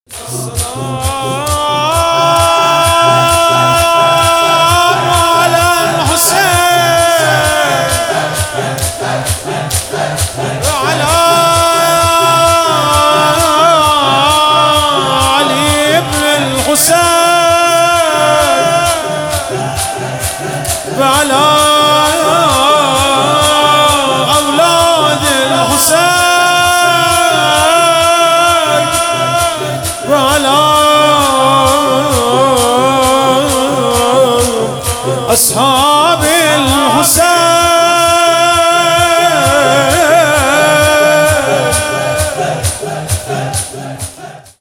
شهادت امام باقر(علیه السلام) شور